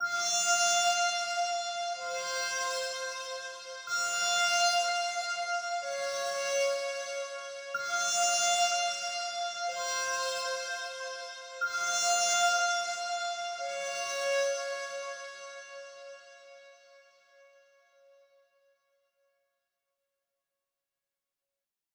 AV_Mythology_Pad_124bpm_Fmin
AV_Mythology_Pad_124bpm_Fmin.wav